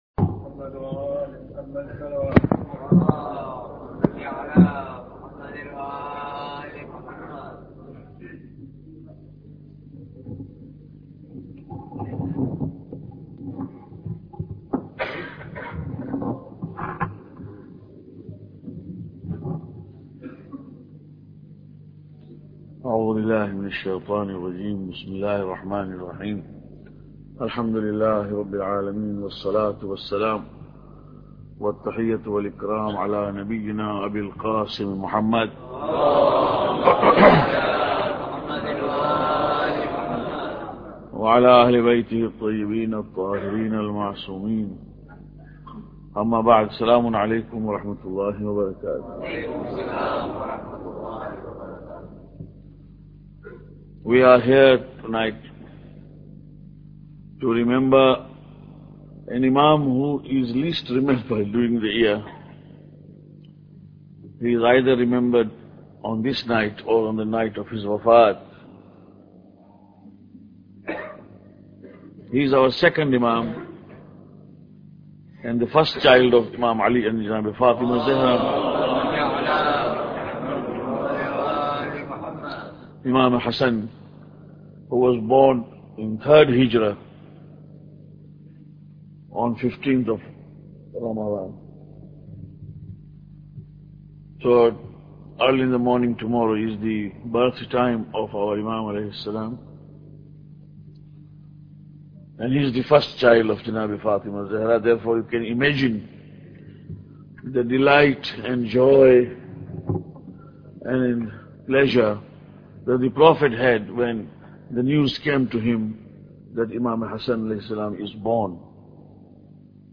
Lecture 3